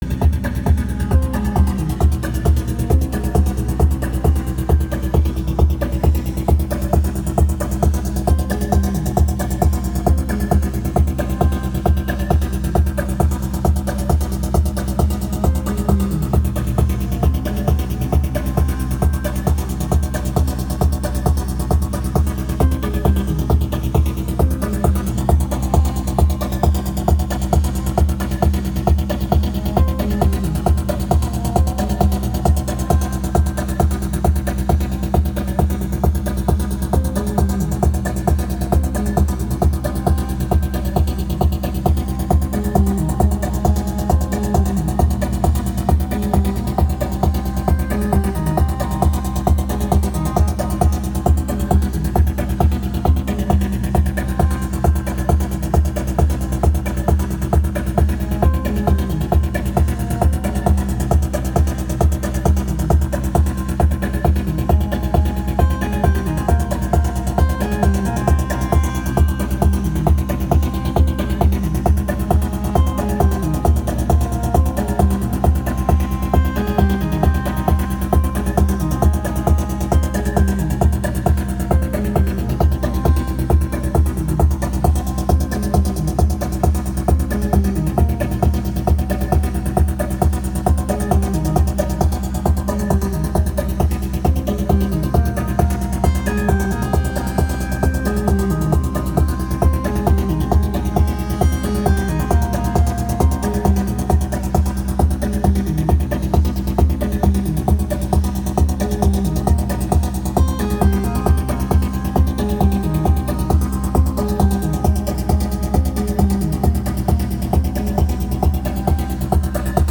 Me and a buddy jamming yesterday